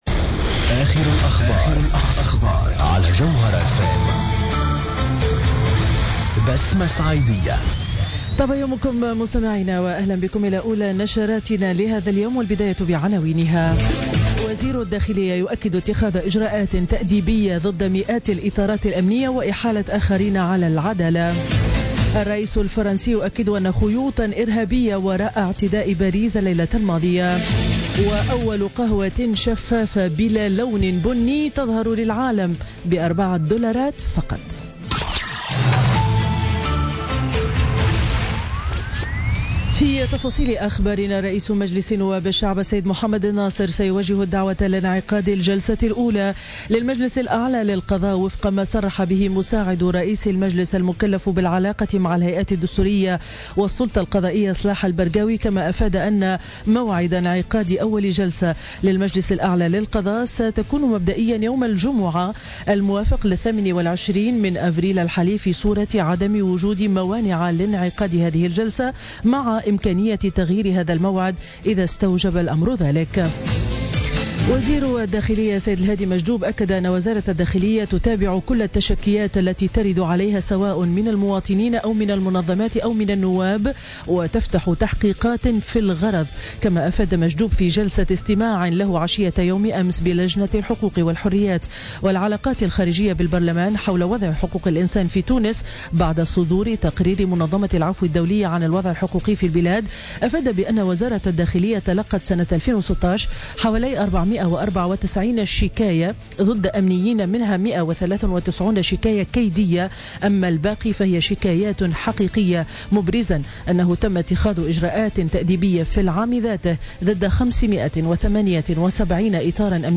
نشرة أخبار السابعة صباحا ليوم الجمعة 21 أفريل 2017